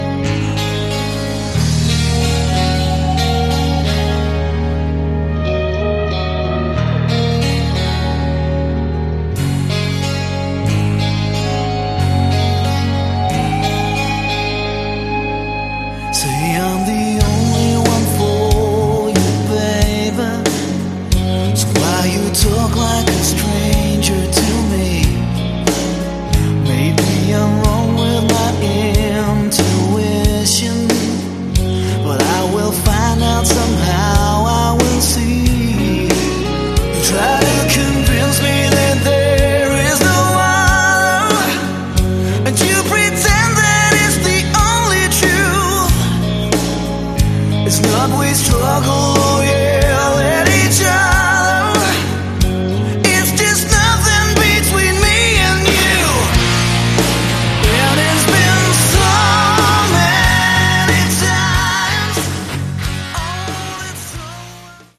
Category: AOR
lead and backing vocals
bass
drums
keyboards
guitars
Top notch AOR from Germany.